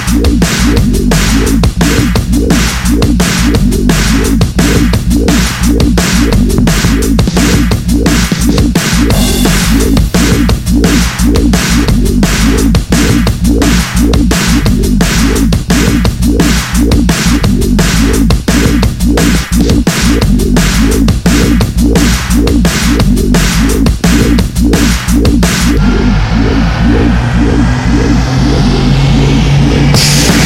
TOP > Jump Up / Drum Step